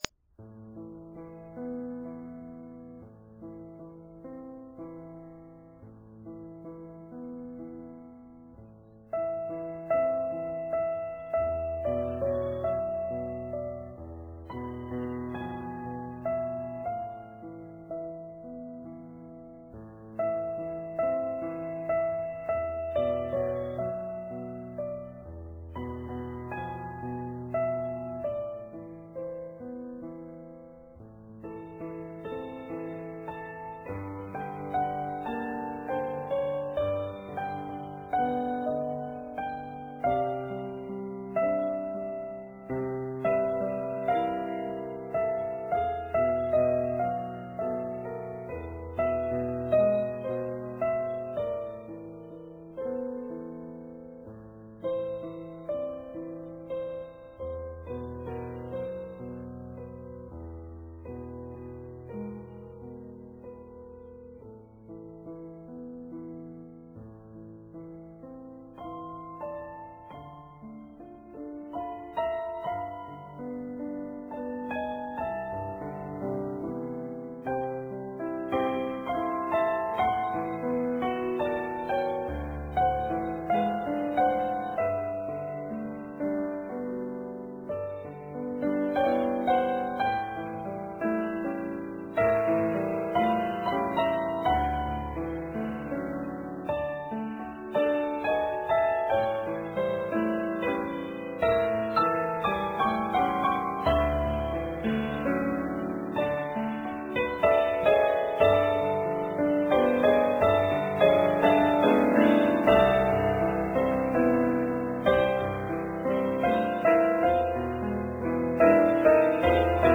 piano
cello